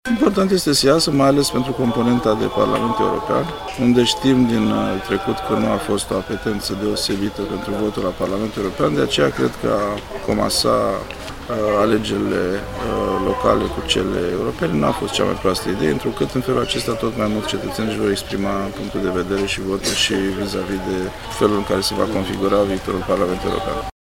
Ministrul Apărării, Angel Tîlvăr, a votat duminică, 9 iunie, la Focșani. Demnitarul a făcut apel la români să se prezinte la vot.